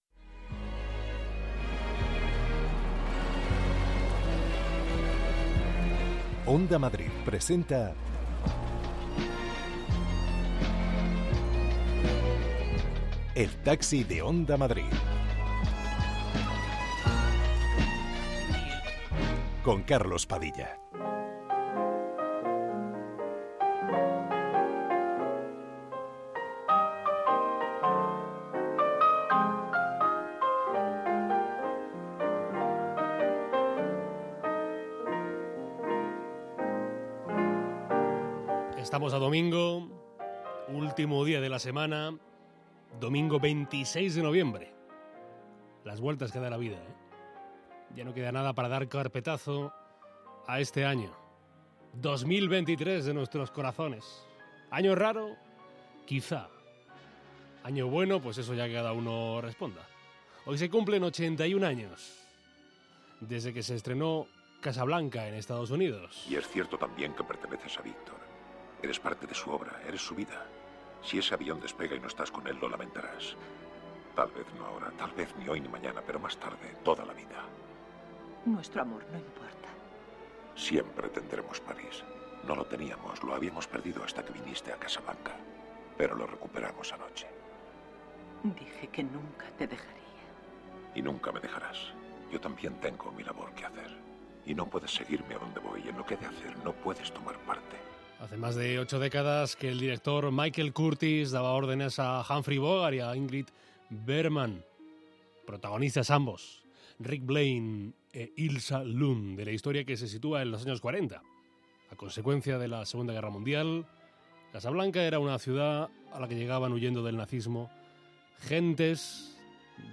Conversaciones para escapar del ruido. Recorremos Madrid con los viajeros más diversos del mundo cultural, político, social, periodístico de España...